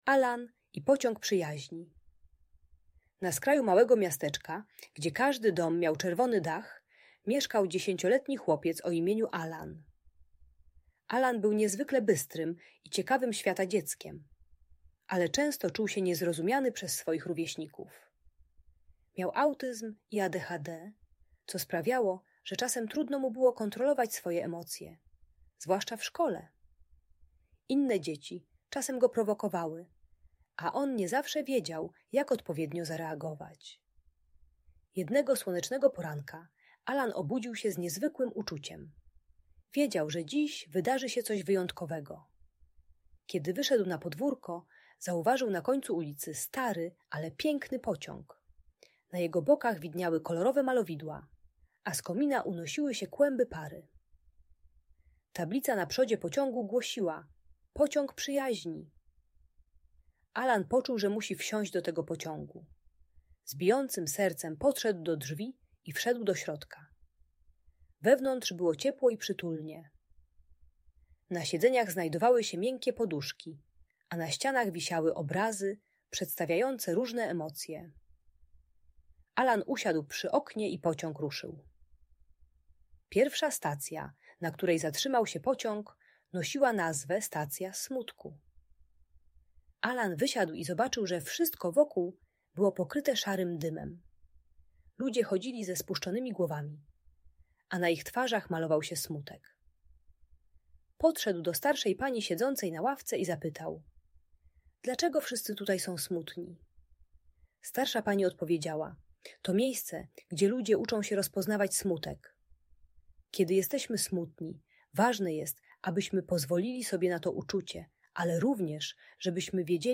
Uczy rozpoznawania emocji (smutek, gniew, radość) oraz techniki głębokiego oddychania, gdy dziecko czuje, że zaraz wybuchnie. Audiobajka o radzeniu sobie ze złością i budowaniu przyjaźni.